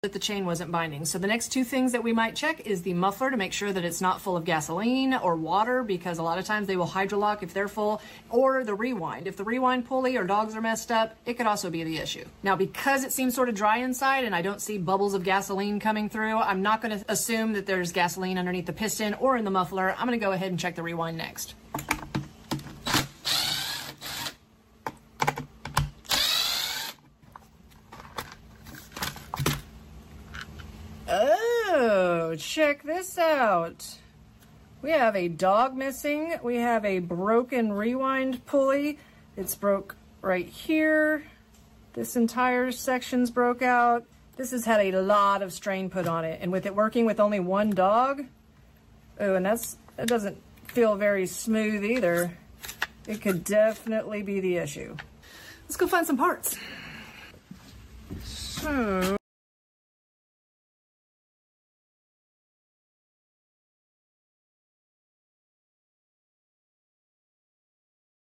Chainsaw Extremely HARD To Pull?